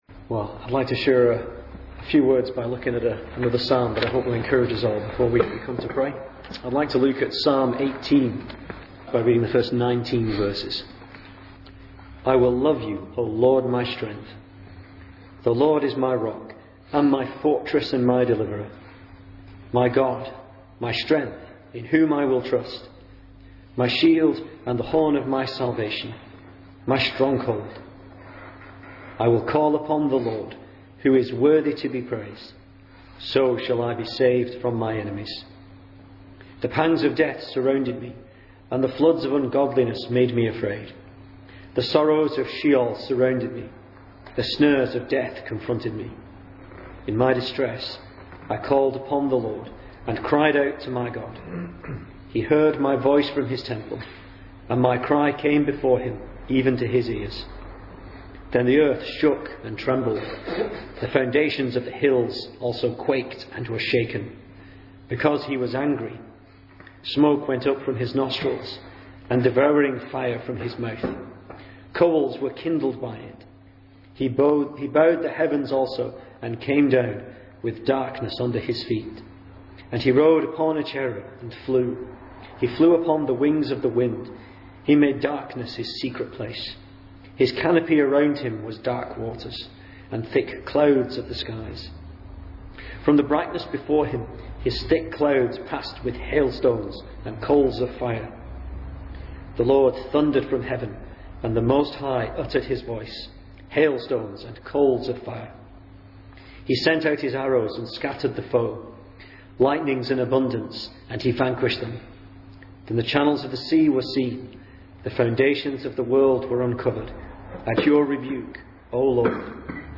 2011 Service Type: Weekday Evening Speaker